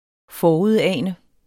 Udtale [ ˈfɒuðˌæˀnə ]